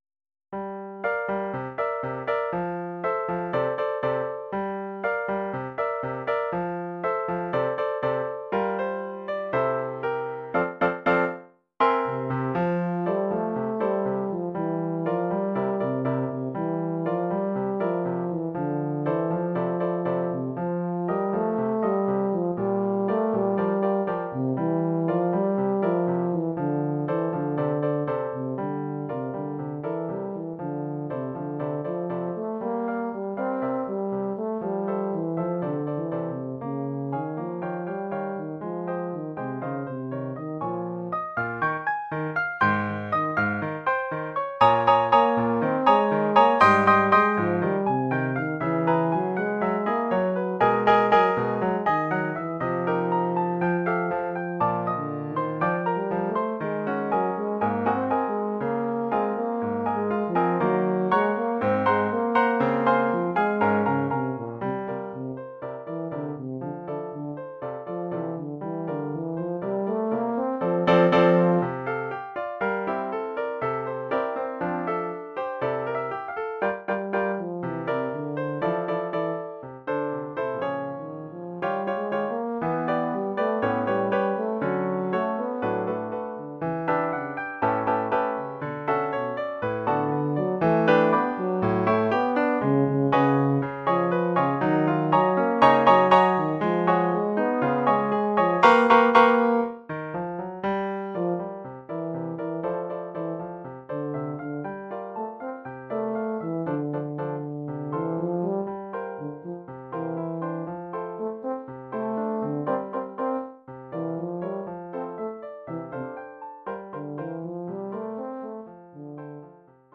Oeuvre pour saxhorn basse et piano.
Oeuvre pour saxhorn basse / euphonium /
tuba et piano.